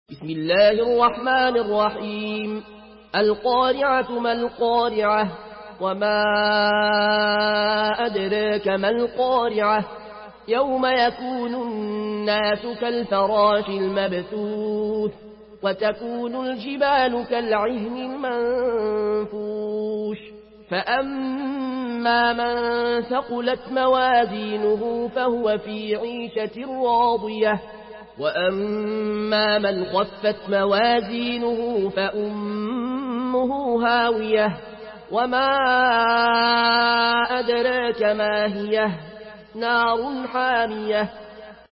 Surah আল-ক্বারি‘আহ্ MP3 by Al Ayoune Al Koshi in Warsh An Nafi From Al-Azraq way narration.
Murattal Warsh An Nafi From Al-Azraq way